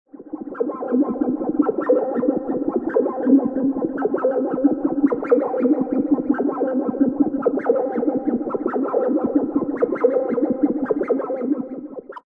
Note that the sound itself is not comming from the LFOs, but from a MIDI synthesizer. The LFOs are used to modulate the sound parameters like VCO Frequency, CutOff Frequency, Resonance, Sync., Effect parameters of the synth.
Using three LFOs: 2 (485 k)